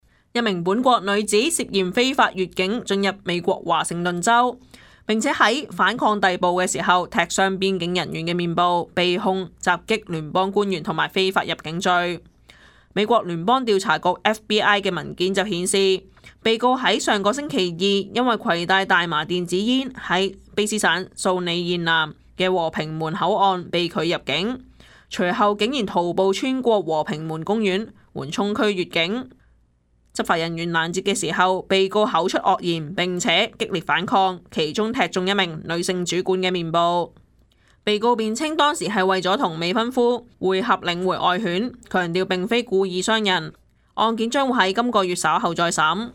news_clip_24656.mp3